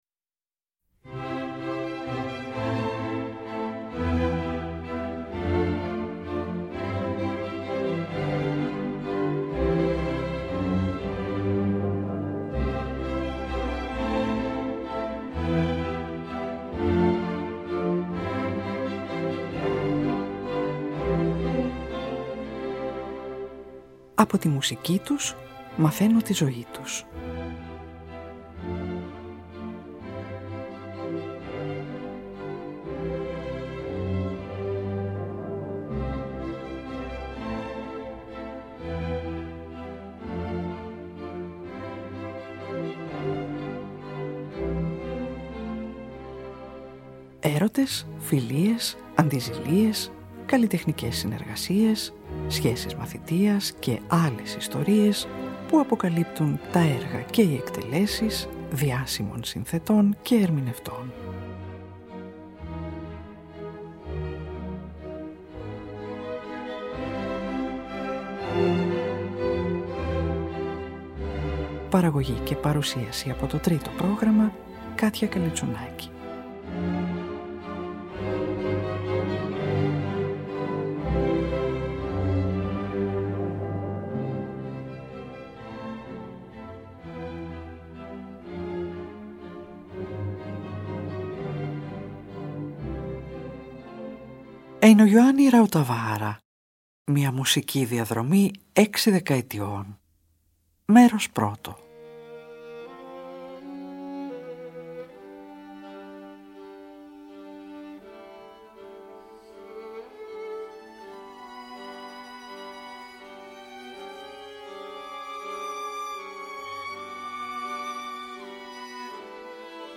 σε εκδοχή για ορχήστρα εγχόρδων
για κλαρινέτο και πιάνο